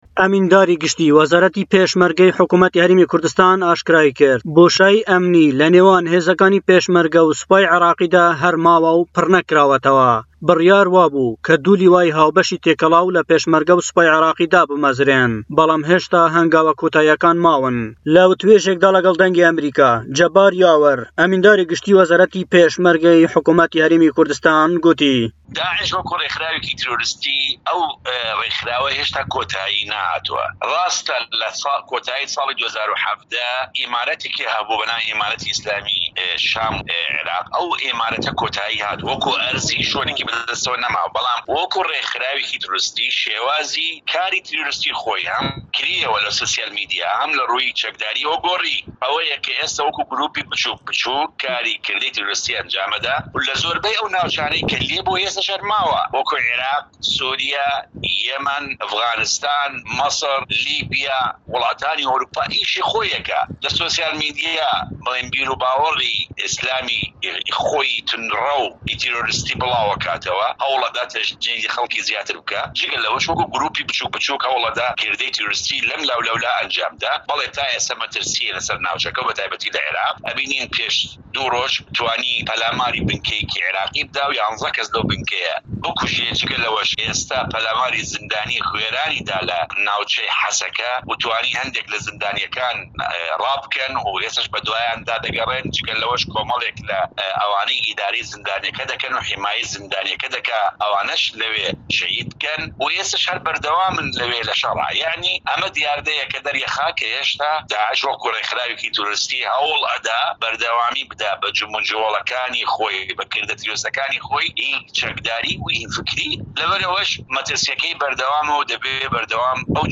لە وتووێژێكدا لەگەڵ دەنگی ئەمەریكا، جەبار یاوەر ئەمینداری گشتی وەزارەتی پێشمەرگەی حكومەتی هەرێمی كوردستان گووتی" داعش شێوازی كاری تیرۆریستی خۆی گۆڕیوە ئێستا وەكو گرووپی بچووك بچووك كردەوەی تیرۆریستی ئەنجام دەدات و لەزۆربەی ئەو ناوچانەی كە لێ بووە ئێستاش هەر ماوە وەكو عێراق، سوریا، یەمەن، ئەڤغانستان، میسر و لیبیا و وڵاتانی ئەوروپا ئیشی خۆی دەكات و لە سوشیاڵ میدیا بیروباوەڕی ئیسلامی توندڕەوی خۆی بڵاودەكاتەوە و هەوڵ دەدات خەڵكی زیاتر هان بدات و تا ئێستا مەترسیە لەسەر ناوچەكە، بینیمان پێش دوو ڕۆژ توانی پەلاماری بنكەیەكی عێراقی بدات و یازدە كەس لەو بنكەیە بكوژێت و جگە لەوەش پەلاماری زیندانی غوێرانی داوە لە ناوچەی حەسەكە و ئێستاش لەوێ هەر بەردەوامن لە شەڕ و مەترسیەكە بەردەوامە".